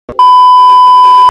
جلوه های صوتی
دانلود صدای بوق سانسور 1 از ساعد نیوز با لینک مستقیم و کیفیت بالا